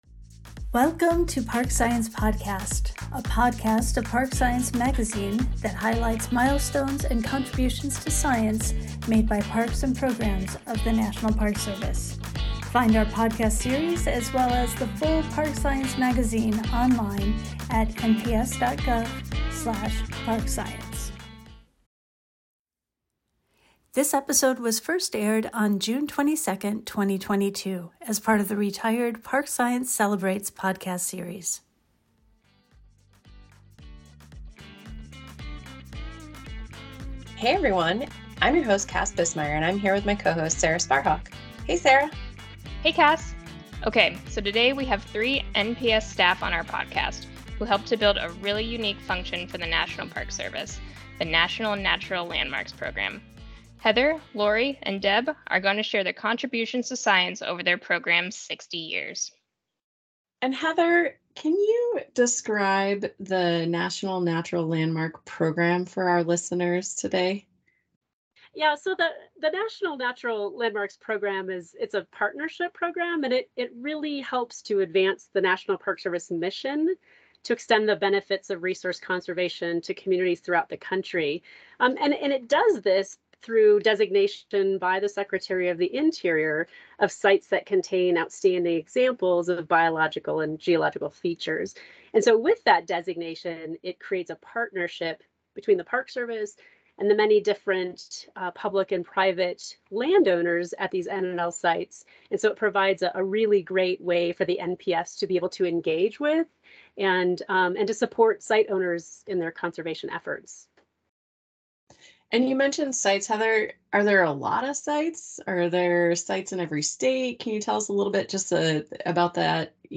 Insightful conversations about science, nature, culture, and complex issues in our national parks.